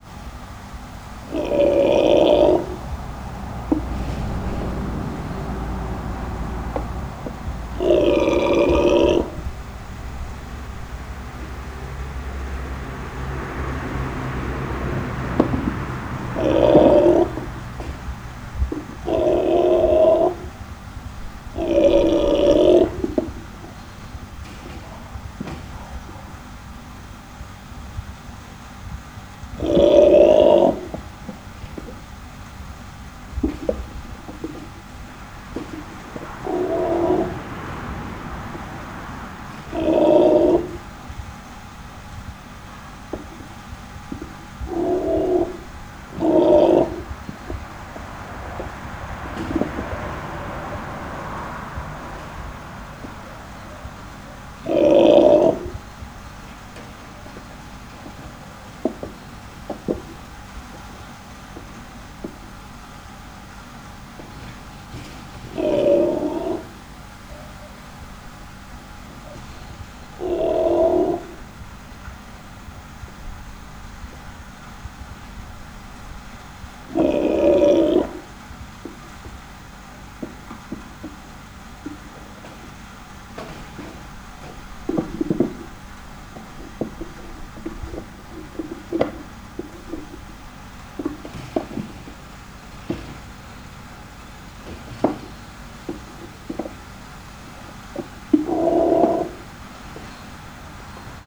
Directory Listing of /_MP3/allathangok/nyiregyhazizoo2010_standardt/takin/
hatterbenuzemzaj_szelvedo01.41.wav